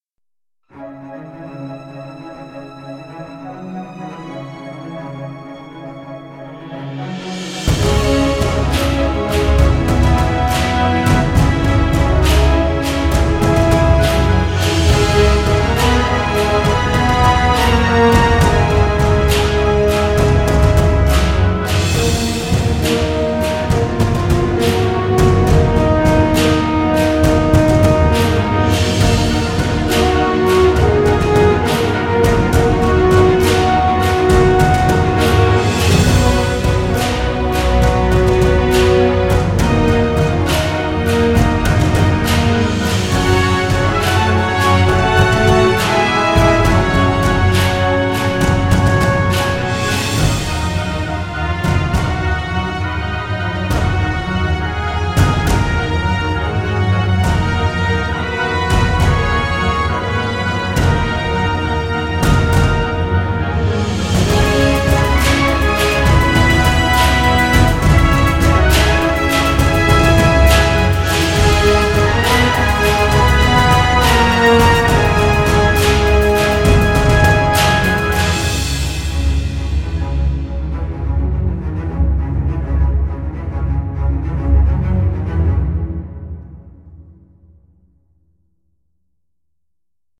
Die epische Titelmusik